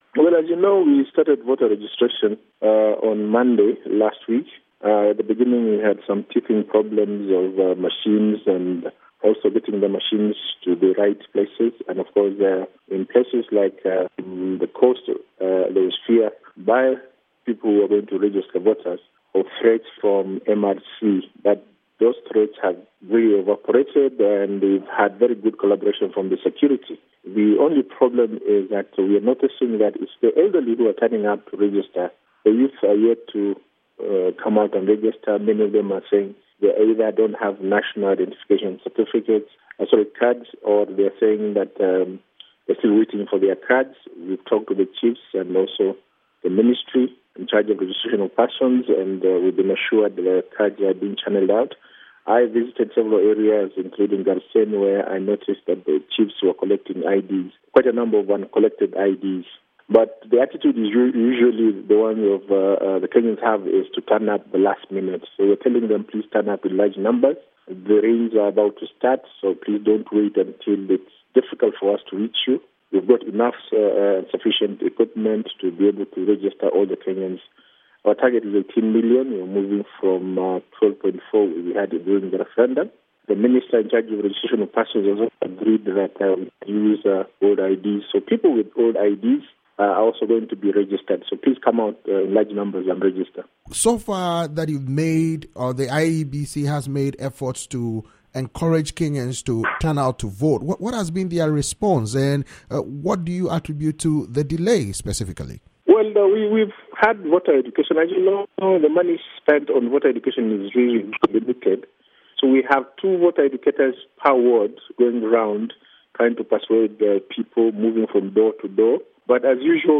interview with Ambassador Yusuf Nzibo, IEBC commissioner